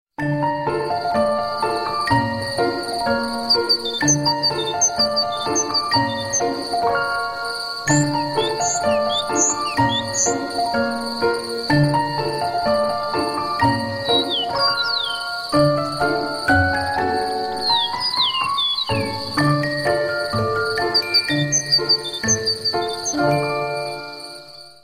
بموسيقى بيانو وأصوات من ليل الطبيعة